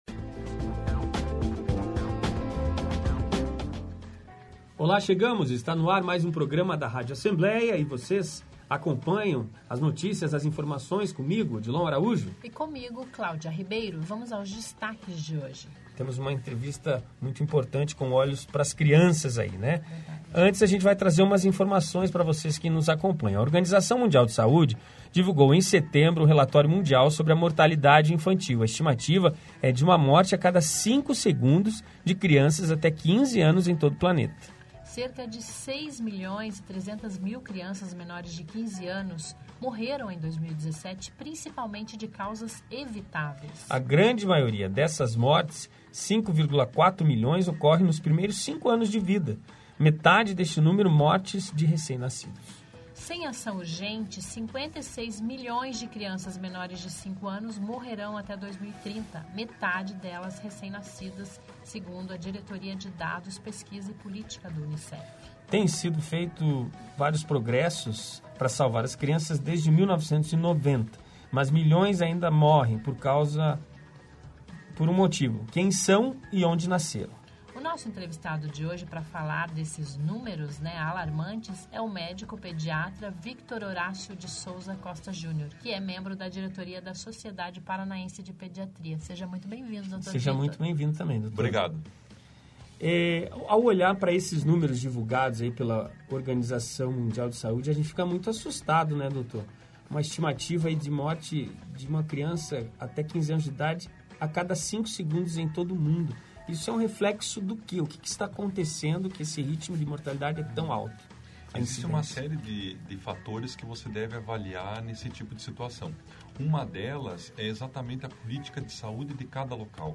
Ouça e assista a entrevista com o pediatra